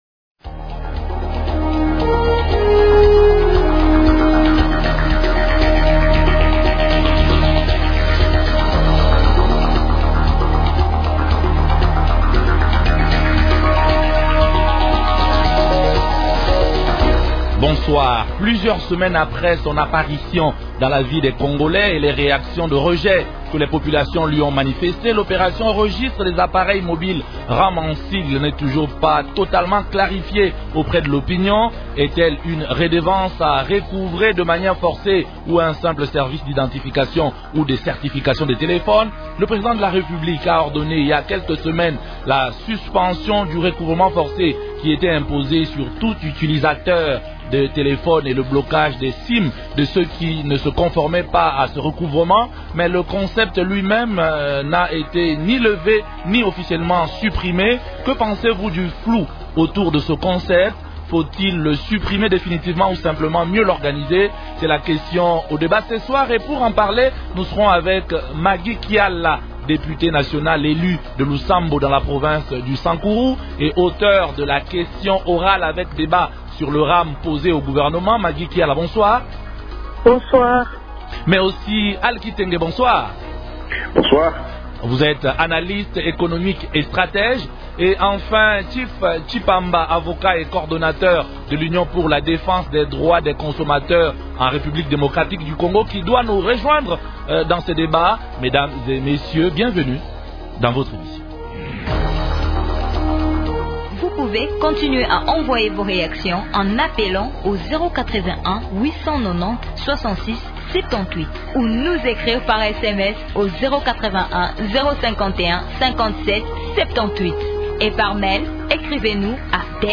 Invités : -Maguy Kiala, députée nationale élue de Lusambo dans la province du Sankuru.